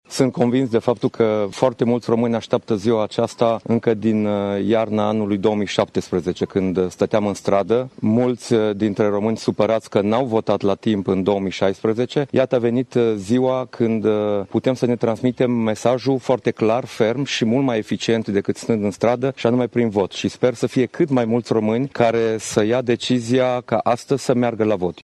Aliatul politic al acestuia, liderul PLUS a votat, duminică, la secţia unde este arondat în orașul Zalău.